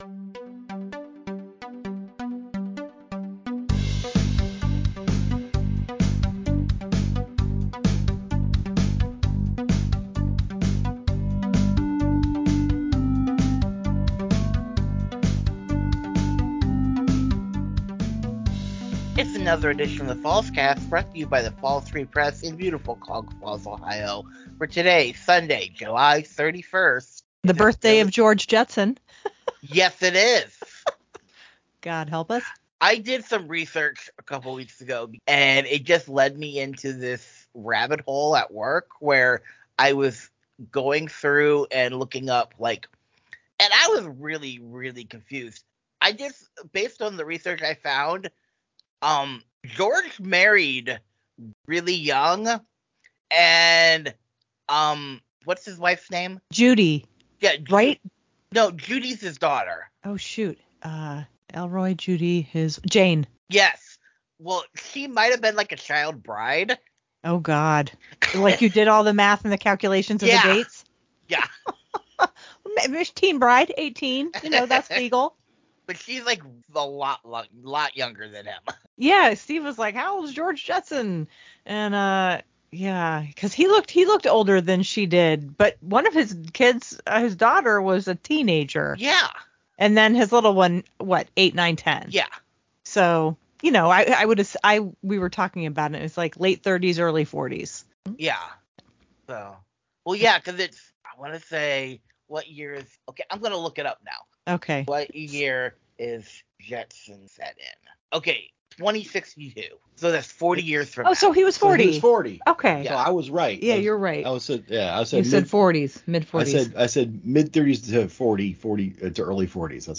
This week’s episode comes to you in a more casual, unstructured conversation as we discuss: